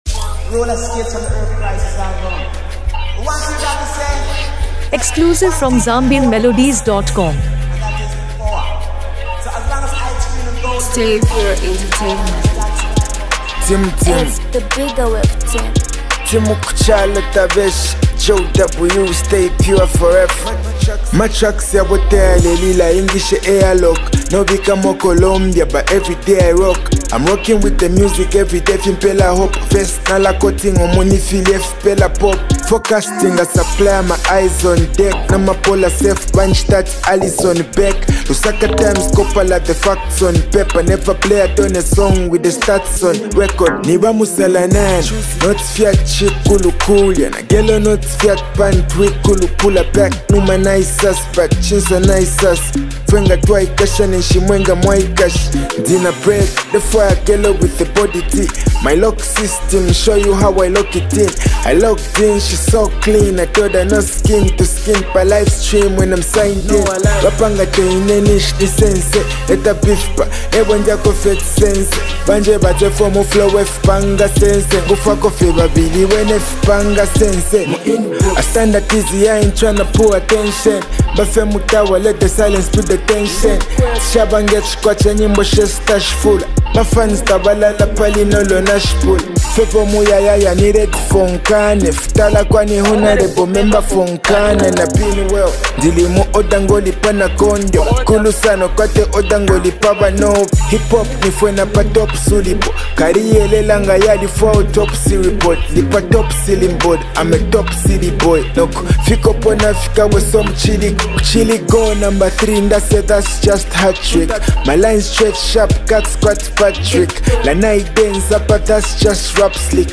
powerful Afro-fusion anthem